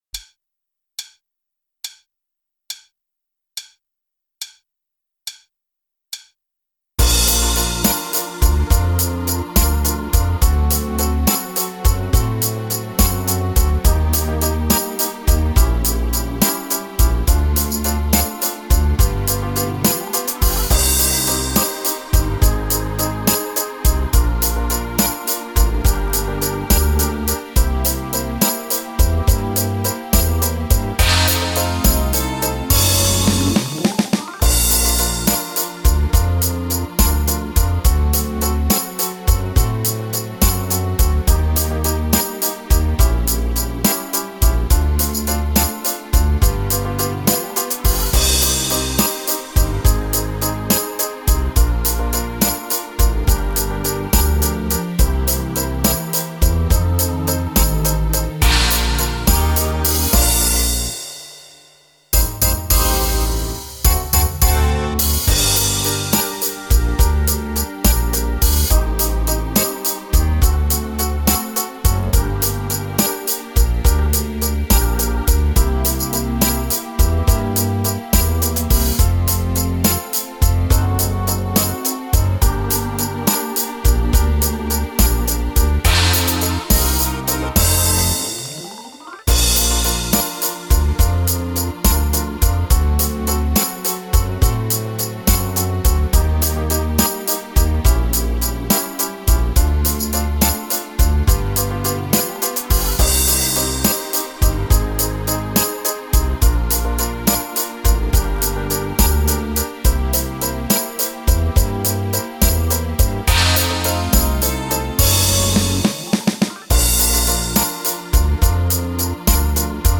Le play back
rythmique